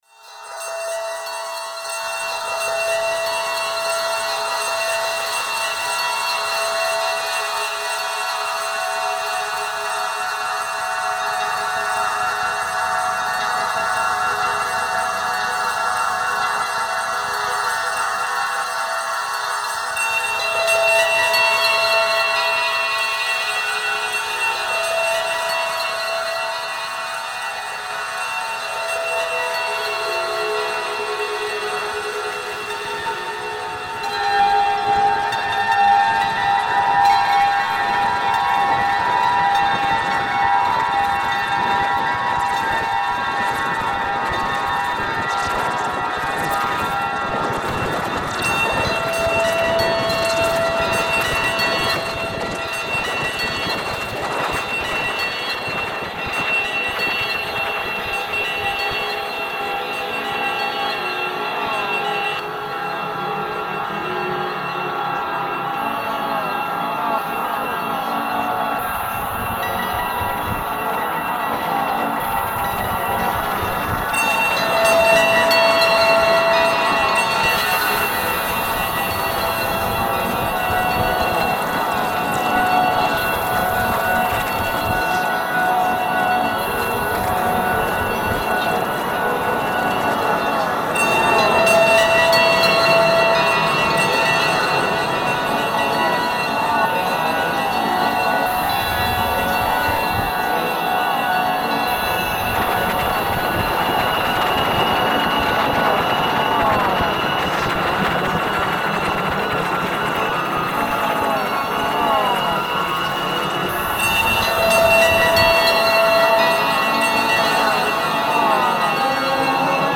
dark ambient
lap harp, guitar, flute, drums and your own voice.